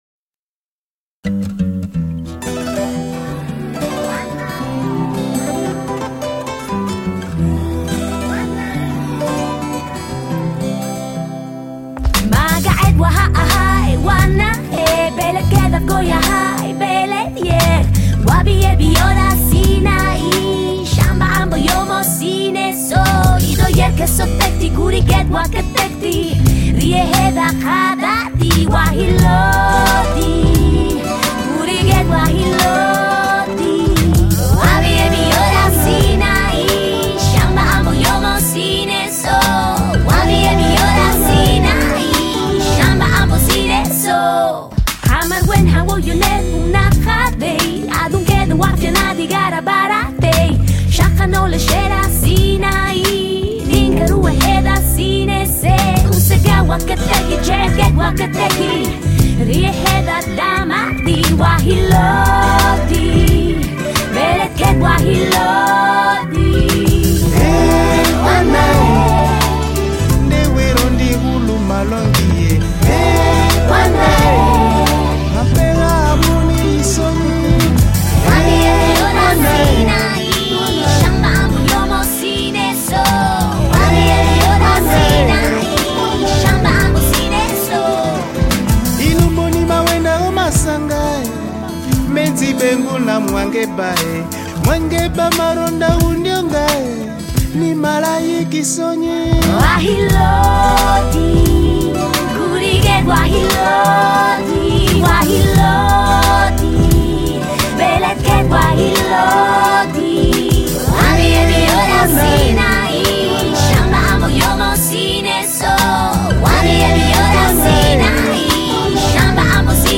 Жанр: Ethnic, World Music, Vocal